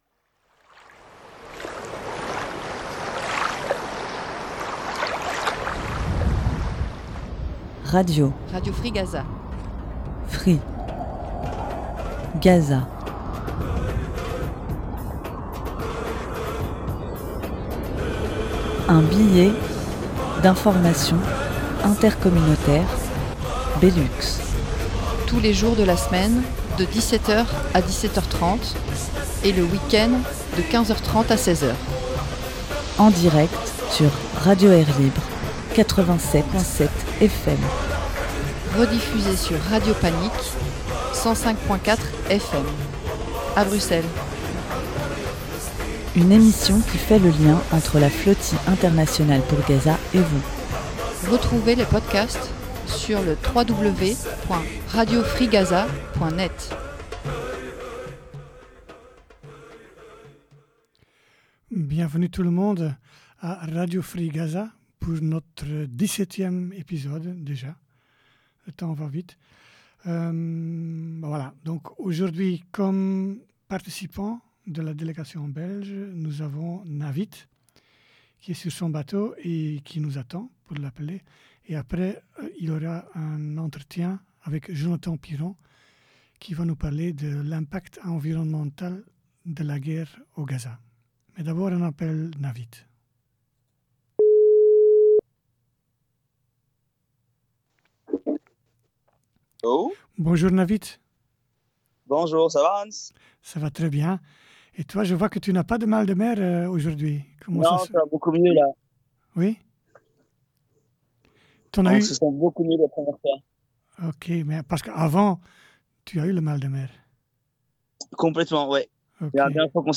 au bout du fil
Ensuite un entretien